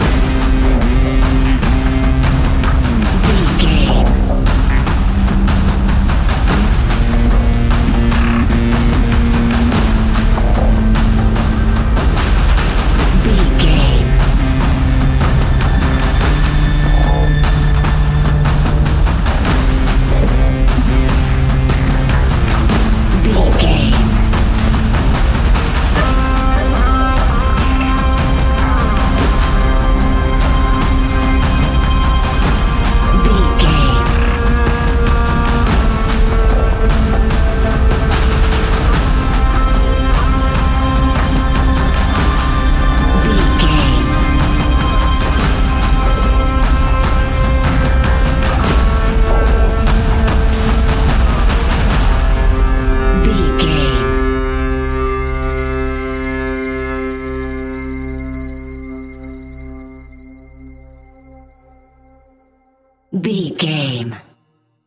Epic / Action
Fast paced
In-crescendo
Ionian/Major
C♯
dark ambient
EBM
synths